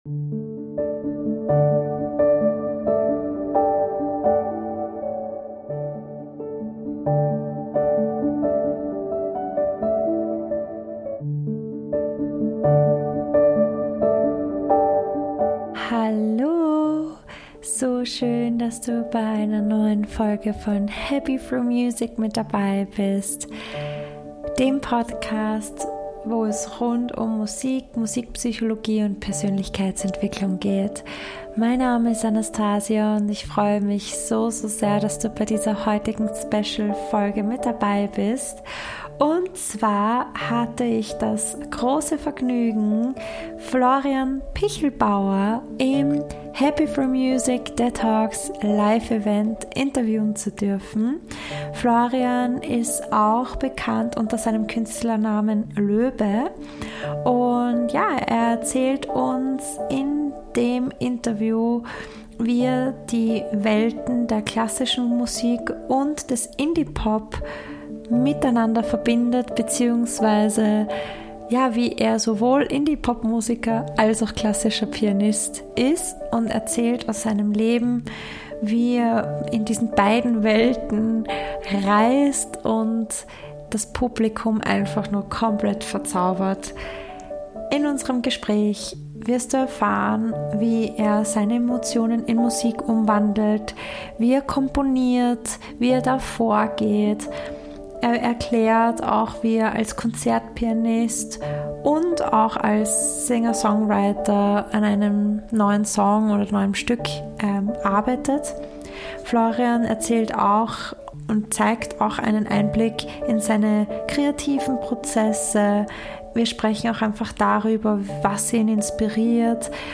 Interview Special mit Pianisten & Singer/Songwriter